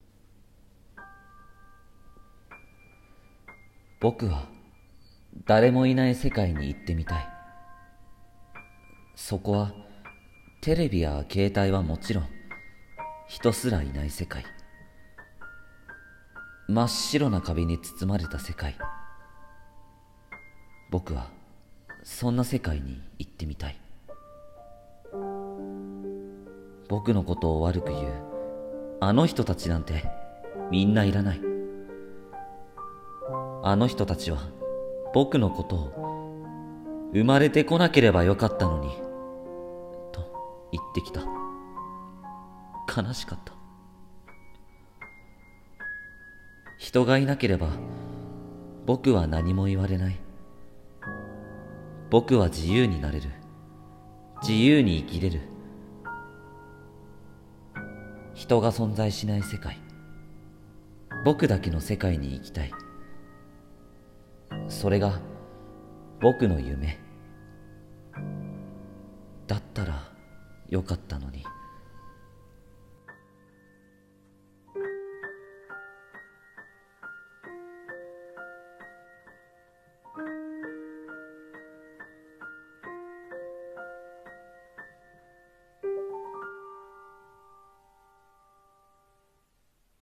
【声劇】誰もいない世界